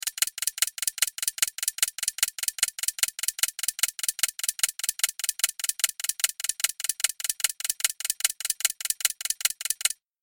جلوه های صوتی
دانلود صدای ساعت 14 از ساعد نیوز با لینک مستقیم و کیفیت بالا